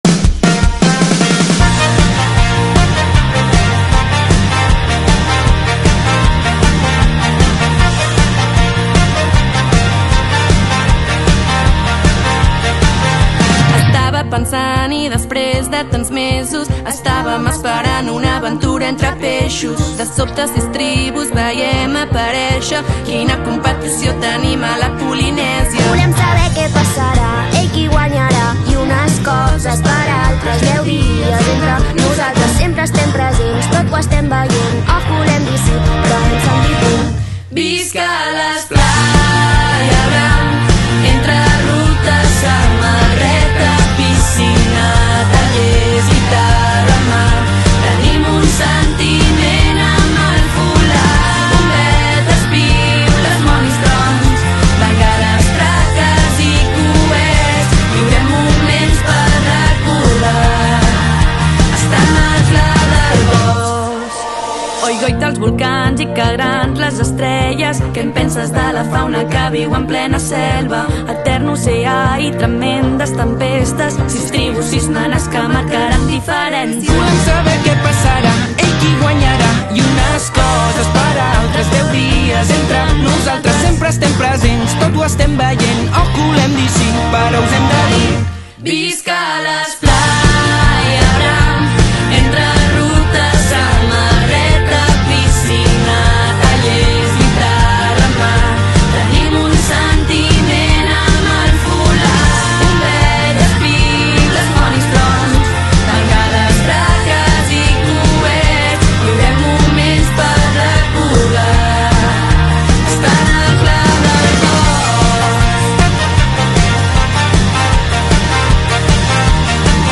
Clar del Bosc 2021